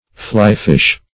Fly-fish \Fly"-fish\, v. i.